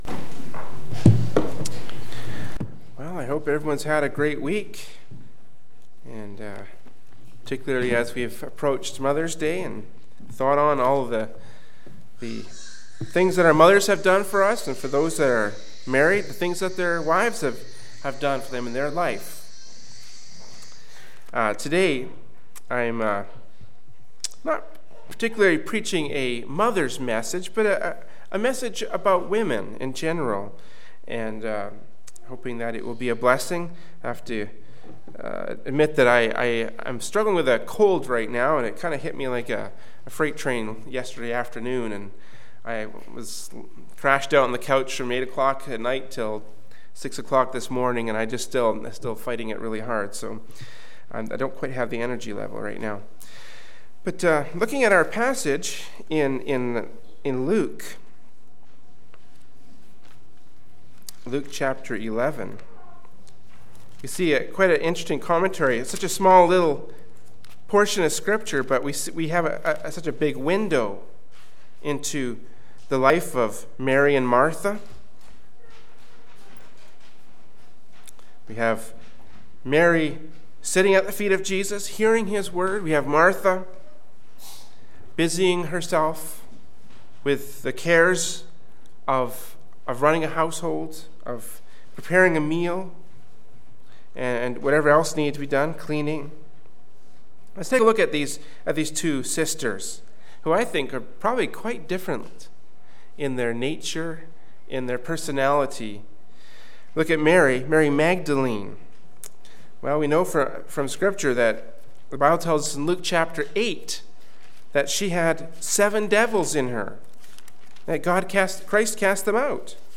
Kamloops, B.C. Canada
Morning Worship Service